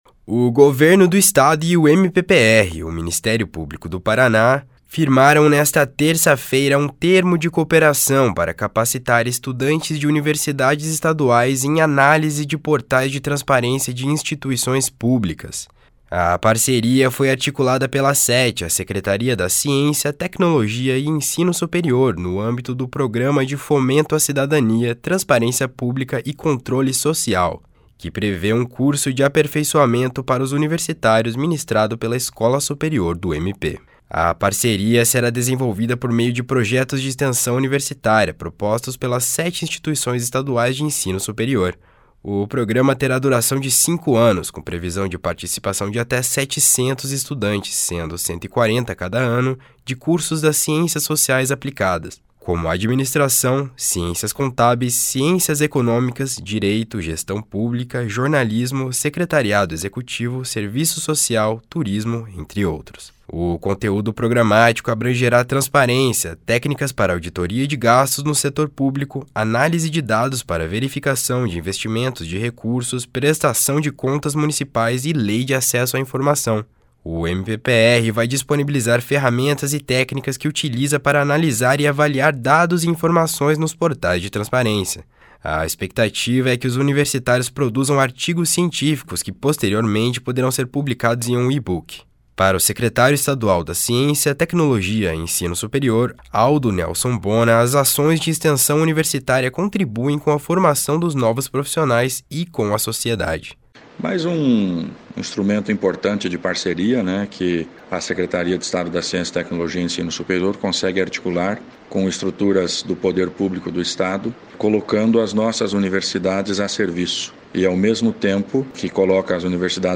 Para o secretário estadual da Ciência, Tecnologia e Ensino Superior, Aldo Nelson Bona, as ações de extensão universitária contribuem com a formação dos novos profissionais e com a sociedade.// SONORA ALDO NELSON BONA//
O reitor da Universidade Estadual de Ponta Grossa, Miguel Sanches Neto, que preside a Associação Paranaense das Instituições de Ensino Superior Público, destacou que a prestação de serviço como prática universitária valoriza a atividade do ensino superior.//SONORA MIGUEL SANCHES NETO//
O promotor de Justiça e chefe do Centro de Apoio Técnico à Execução, Joelson Luis Pereira, responsável pela área da transparência no MPPR, reforçou a importância de compreender os dados publicados nos portais.//SONORA JOELSON LUIS PEREIRA//